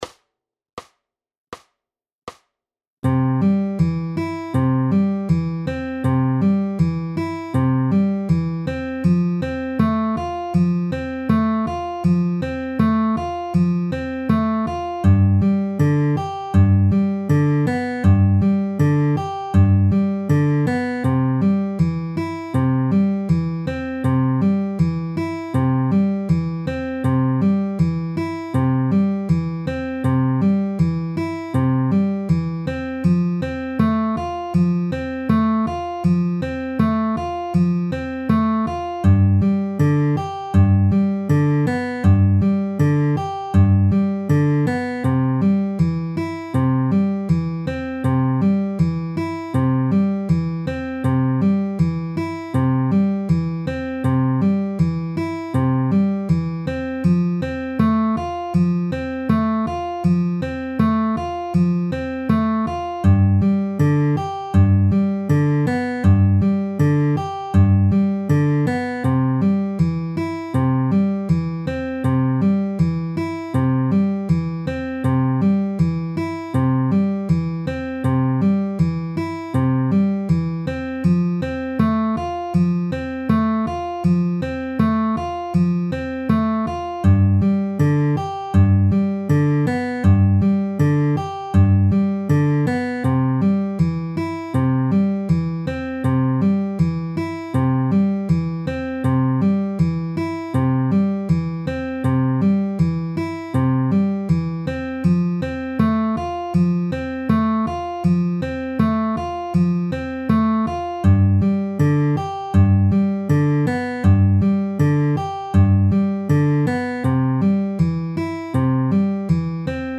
Fingerstyle, Travis Picking, Ring Finger Variations, Inner-Outer play-alongs - Guitar Lessons in Myrtle Beach, SC
C-F-G-C-inner-outer-ring-middle-at-80-bpm.mp3